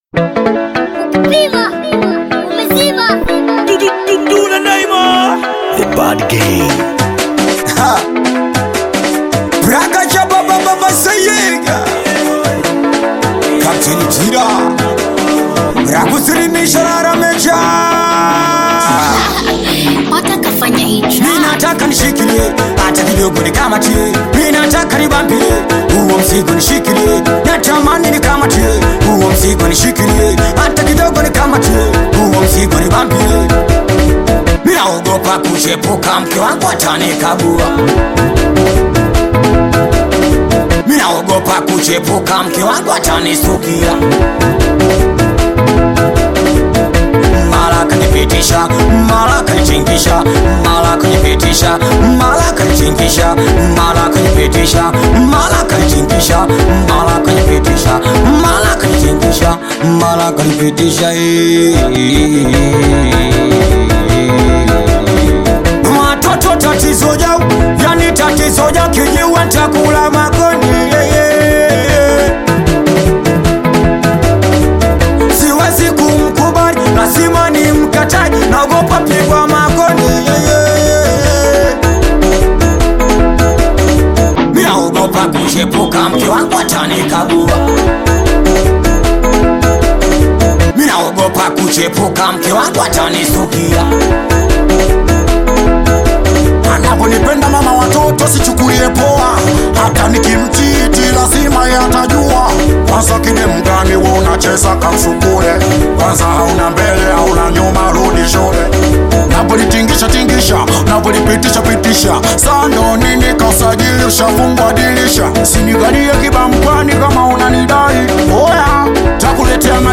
Singeli You may also like